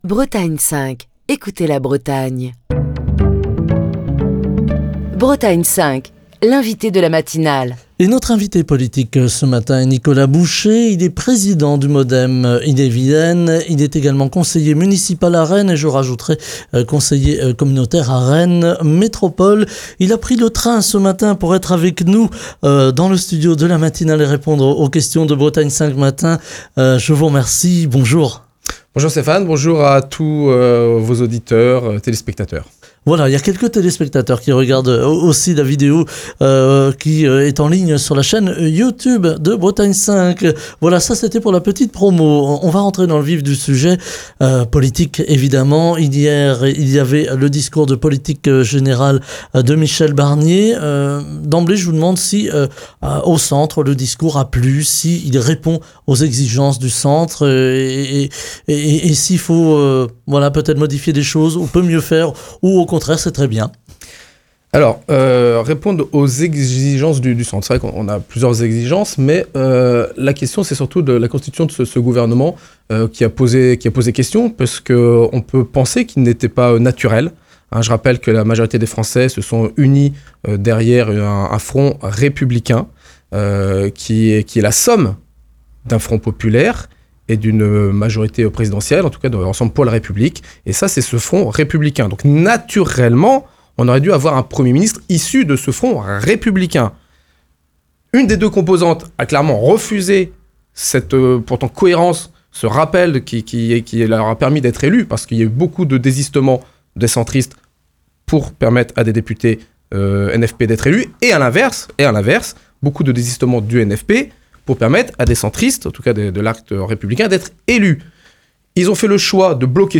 Au lendemain du discours de politique générale de Michel Barnier, Nicolas Boucher, président du MoDem en Ille-et-Vilaine, conseiller municipal et communautaire à Rennes, est l'invité politique de la matinale de Bretagne 5 pour commenter les grands axes et les premières mesures annoncés par le Premier ministre. Nicolas Boucher, revient sur la composition du gouvernement et la nécessité d'une unité transpartisane face aux urgences, notamment en matière de budget, de fiscalité et de sécurité.